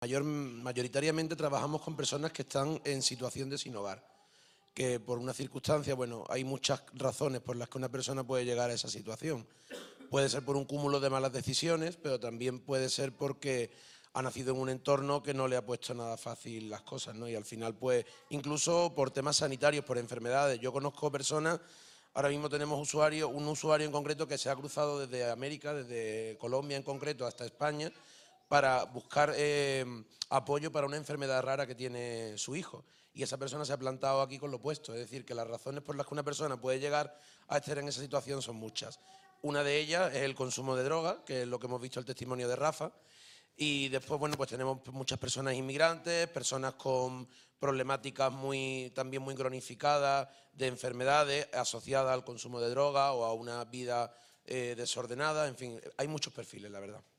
El acto de entrega de los cheques solidarios del programa ‘Gracias A Ti’ reunió a trabajadores de todas las áreas ejecutivas del Grupo Social ONCE (ONCE, Fundación ONCE e ILUNION) en un acto celebrado en el Palacete de los Duques de Pastrana en Madrid el pasado 10 de febrero, que también pudo seguirse en streaming.